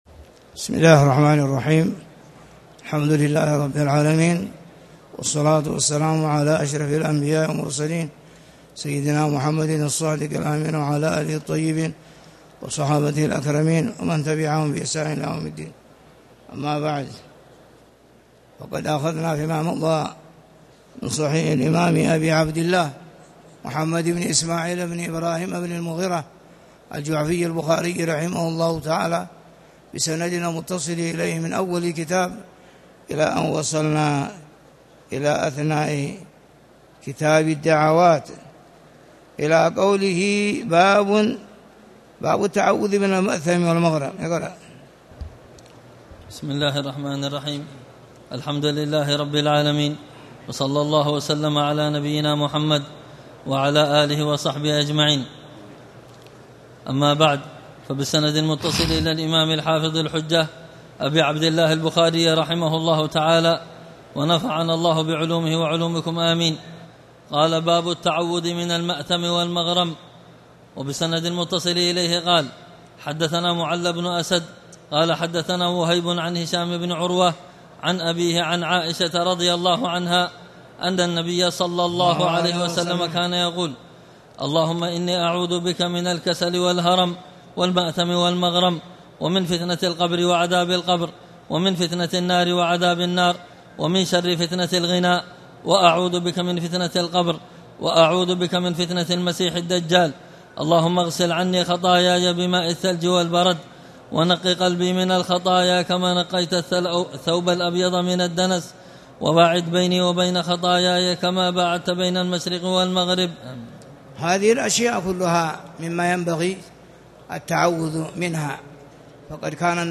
تاريخ النشر ١٨ شعبان ١٤٣٨ هـ المكان: المسجد الحرام الشيخ